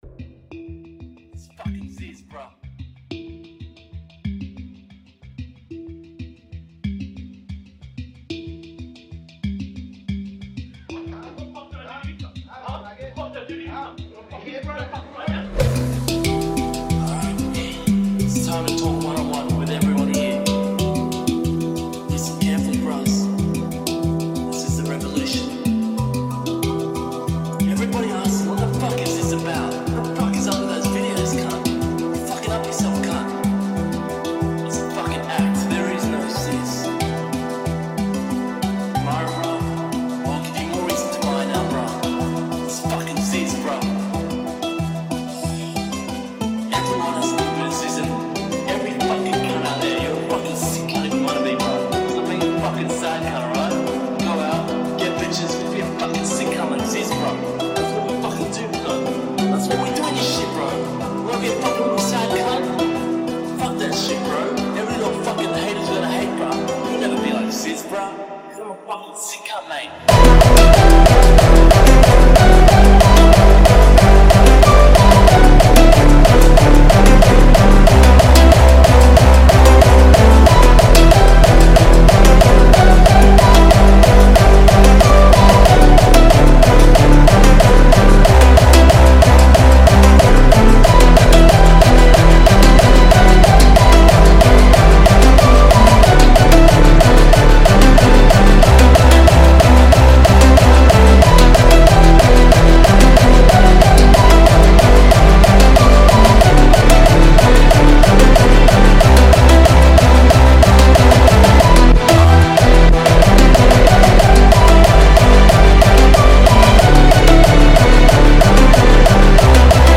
Hardstyle